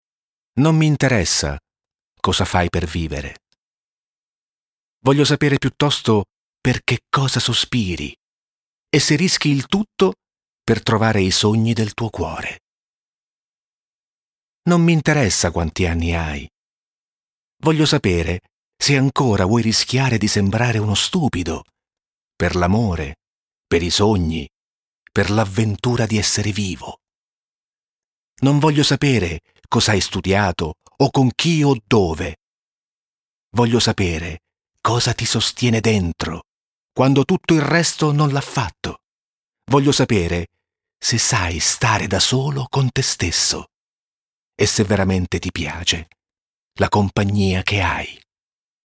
LETTURE DRAMMATIZZATE